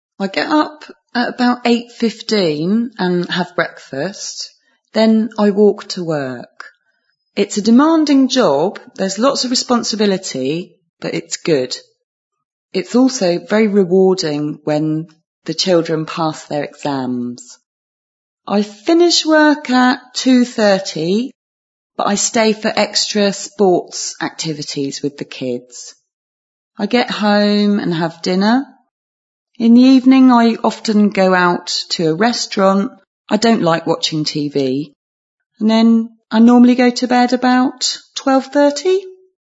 Listen to three people talking about the work that they do.
Speaker 2